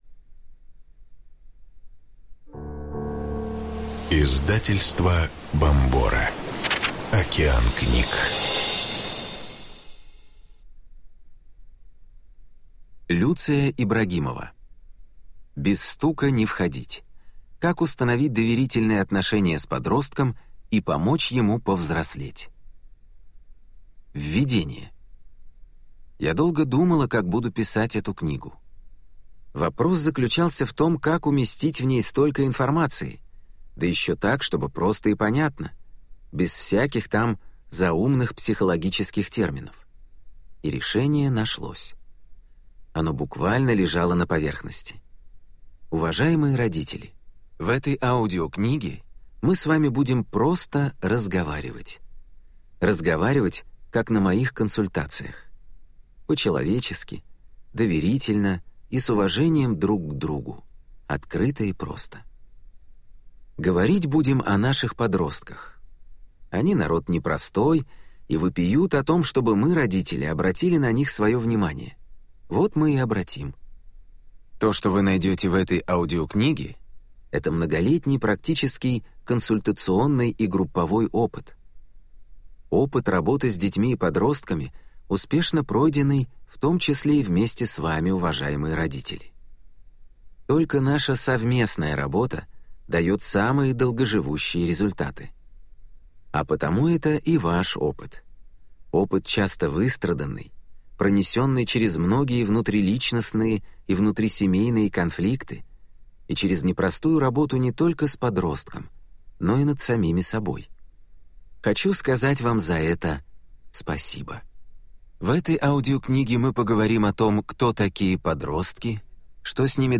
Аудиокнига Без стука не входить! Как установить доверительные отношения с подростком и помочь ему повзрослеть | Библиотека аудиокниг